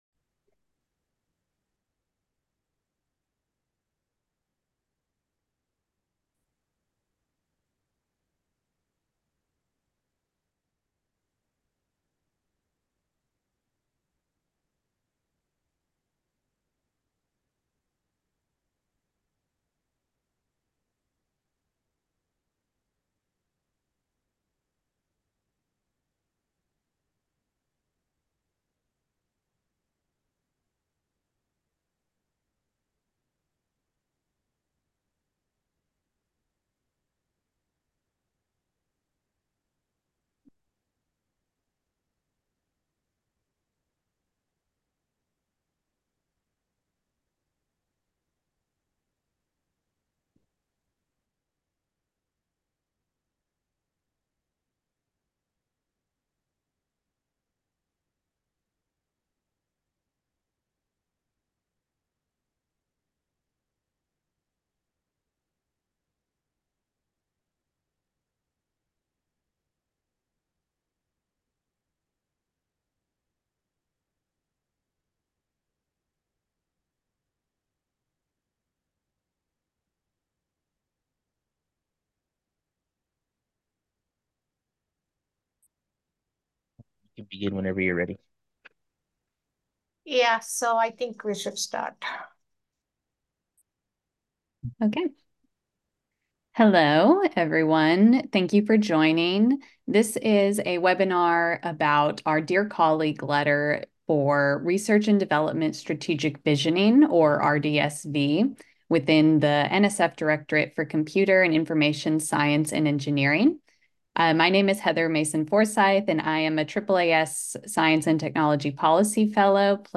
Future Research Directions for the CISE Community (CISE-RV) Program Webinar
CISE RDSV Program Officers